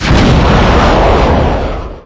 rocket_shoot.wav